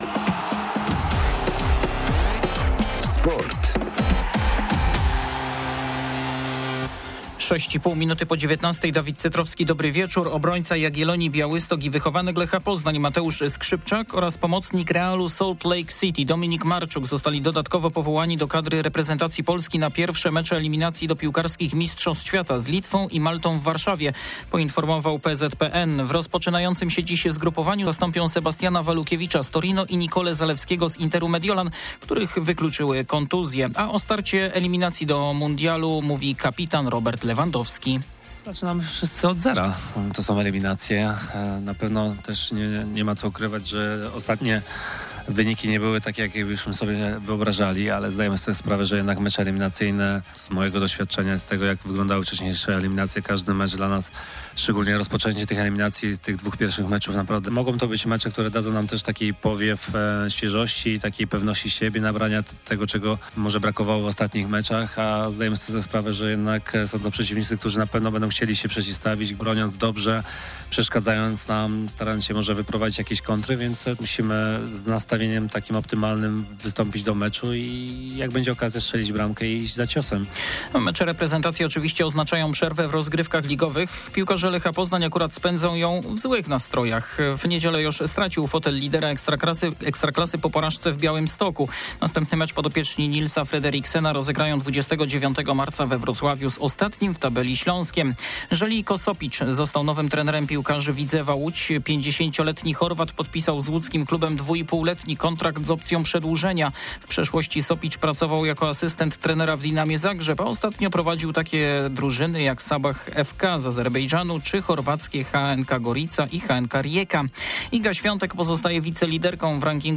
17.03.2025 SERWIS SPORTOWY GODZ. 19:05